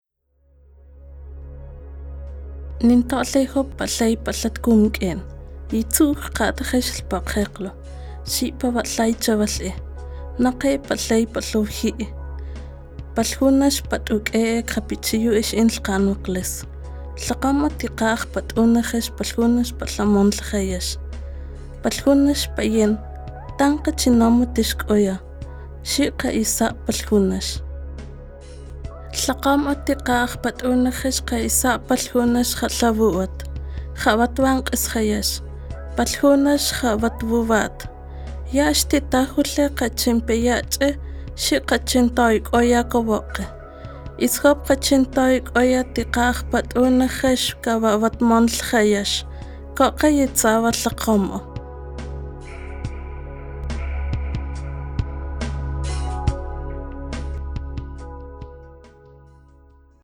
Spots Radiales